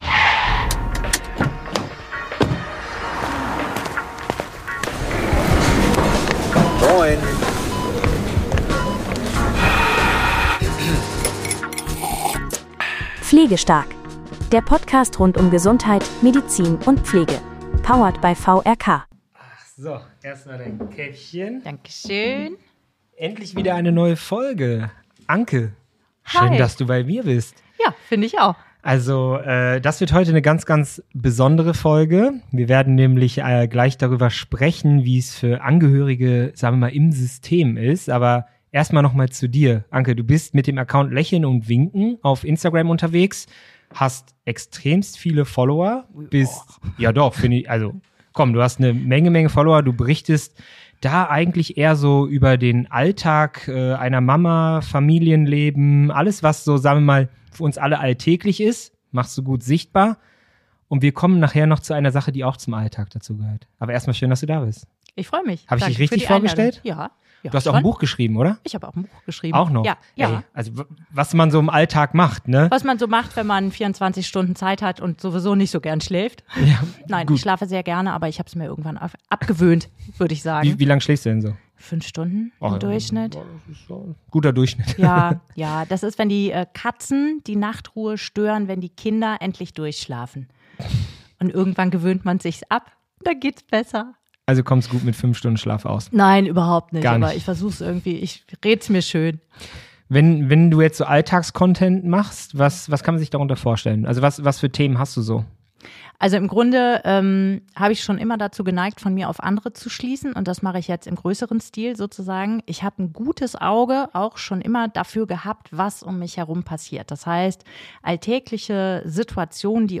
Warum sprechen Pflegende und Angehörige nicht dieselbe Sprache – obwohl sie das gleiche Ziel haben? Ein Gespräch über Schmerz, Sprachlosigkeit – und darüber, warum Menschlichkeit nicht vom Dienstplan abhängen darf.